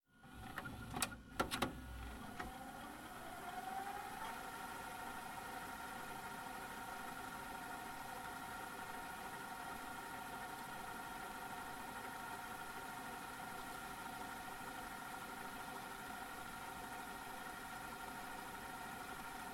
VHS_Rewind.wav